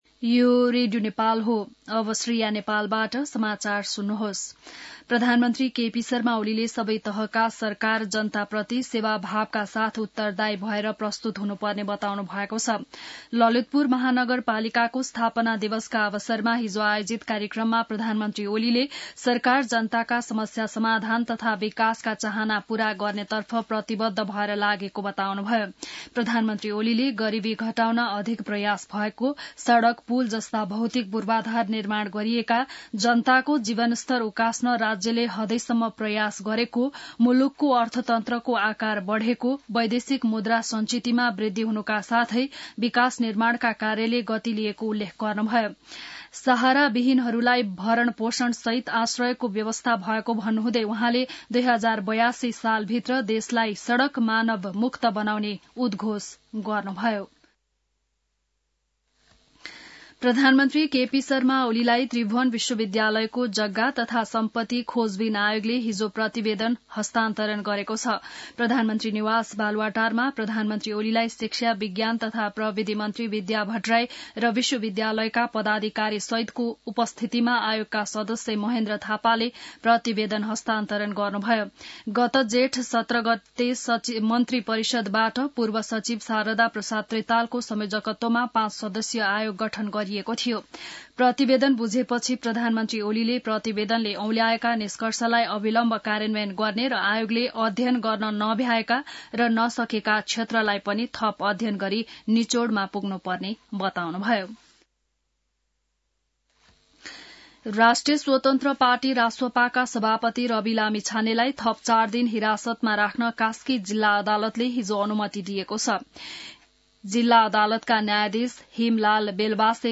बिहान १० बजेको नेपाली समाचार : ४ पुष , २०८१